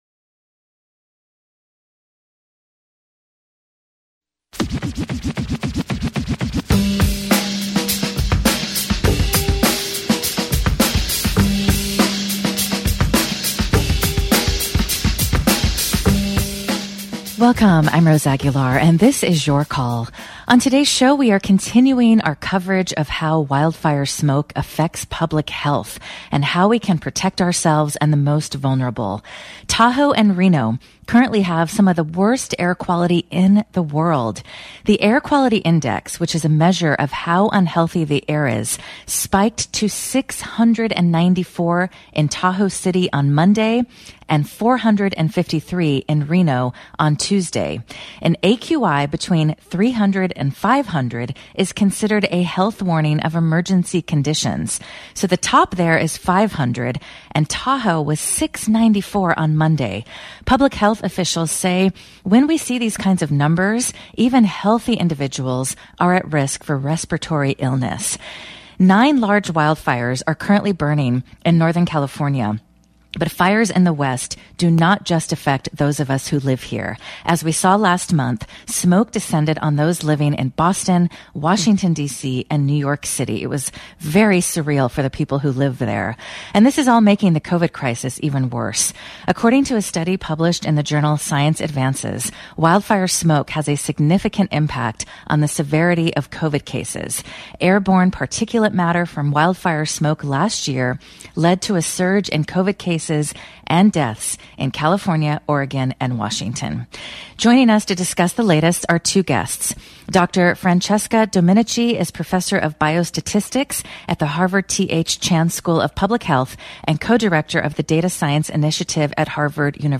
and PHI’s Science for Toxic Exposure Prevention Hear the interview from KALW.